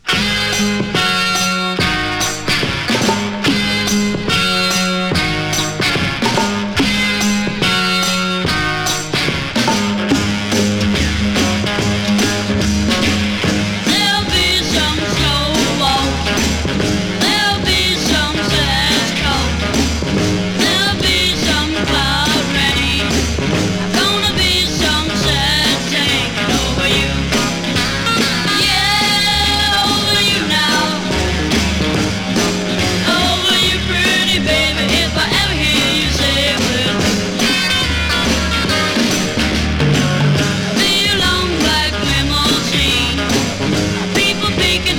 Rock, Pop, Garage　USA　12inchレコード　33rpm　Mono